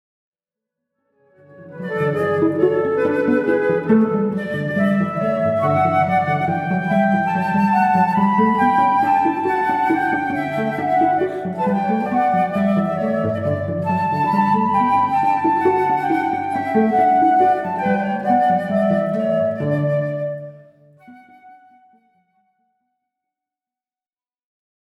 en ré majeur-Allemanda-Presto